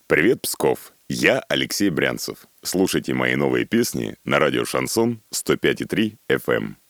Певец Алексей Брянцев поздравил радио «Шансон» с началом вещания в Пскове.